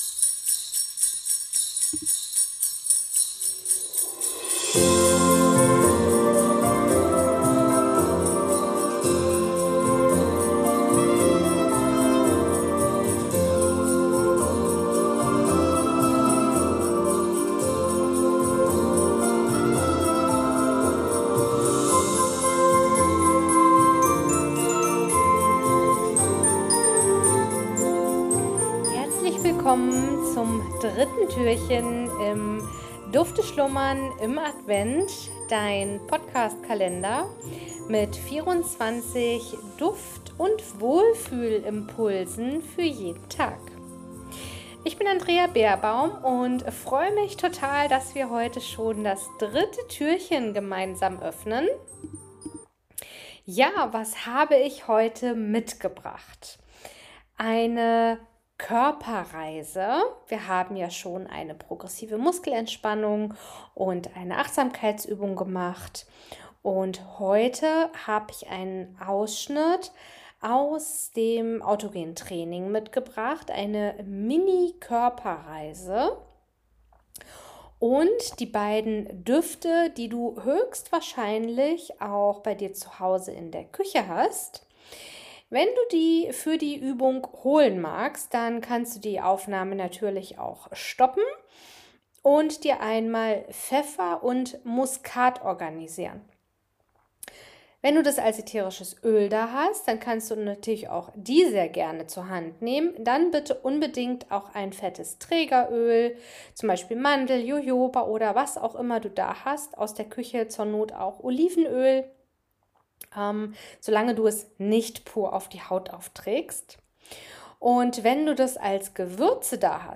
Körperreise (Autogenes Training) mit den Düften Pfeffer/Muskat für das Solarplexus, Sonnengeflecht ist strömend warm oder den Düften Orange/Zitrone um die Sonne zu visualisieren.